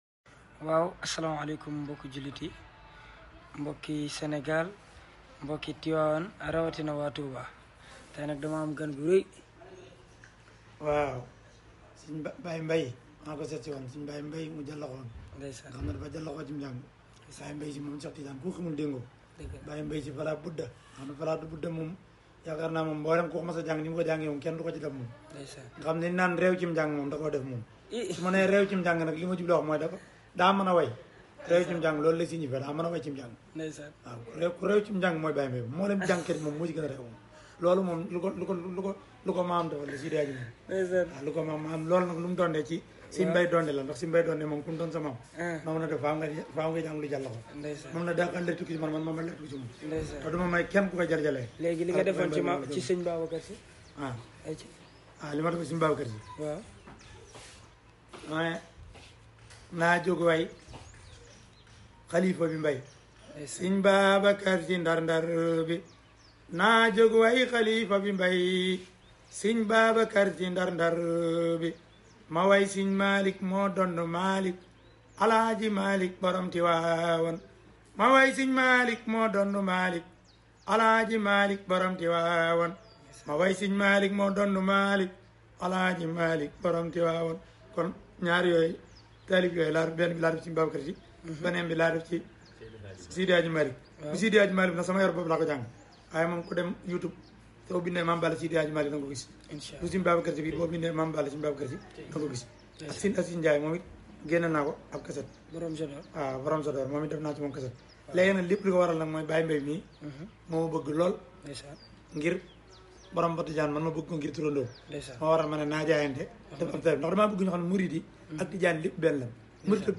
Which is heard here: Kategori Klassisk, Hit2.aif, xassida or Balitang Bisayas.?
xassida